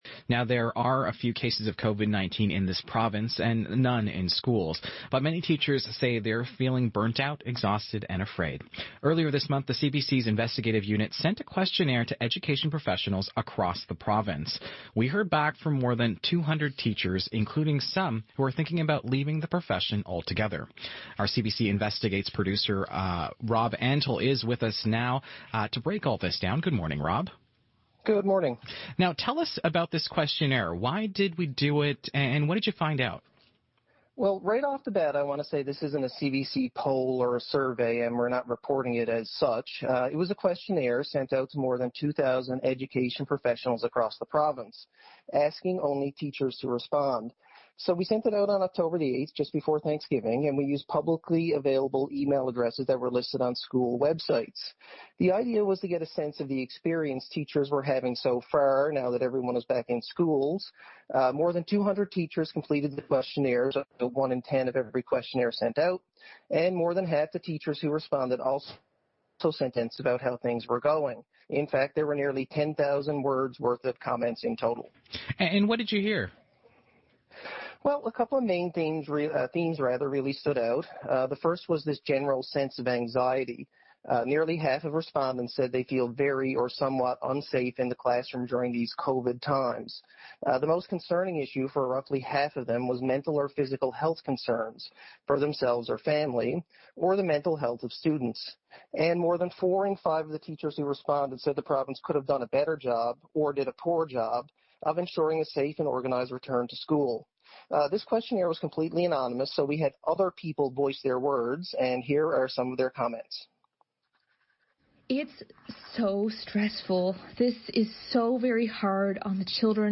Media Interview - CBC East Coast Morning Show - Oct 28, 2020